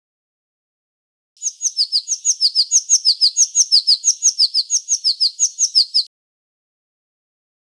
Pimpelmeeszang zonder triller
Zangtypen zonder triller bestaan uit meerdere frasen en bezitten geen triller op het einde.
pimpelmeeszang zonder triller.wav